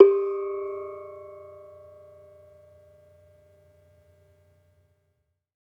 Gamelan
Bonang-G3-f.wav